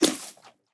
sounds / mob / goat / eat3.ogg
eat3.ogg